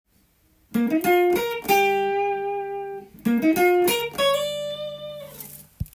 ①のフレーズは、誰でも弾けそうな簡単な雰囲気ですが
９ｔｈの音を使い歌うようなフレーズになっています。